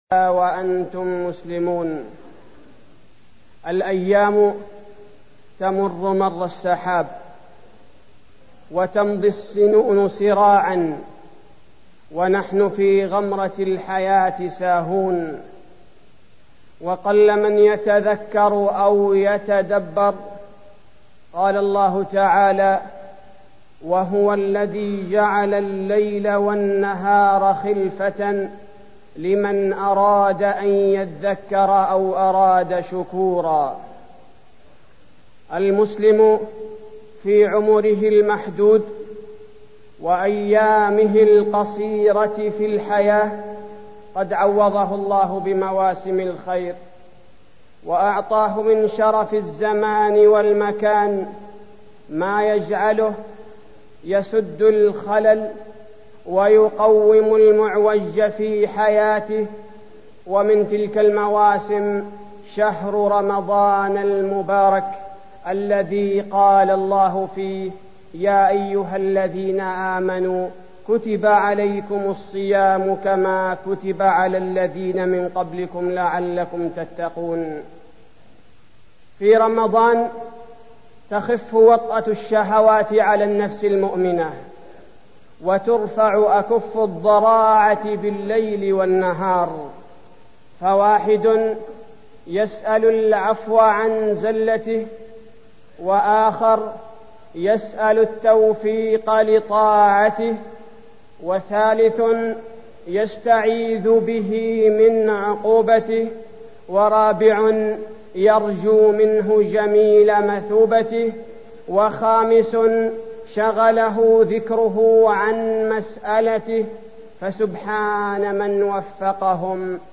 تاريخ النشر ٤ رمضان ١٤١٨ هـ المكان: المسجد النبوي الشيخ: فضيلة الشيخ عبدالباري الثبيتي فضيلة الشيخ عبدالباري الثبيتي فضل شهر رمضان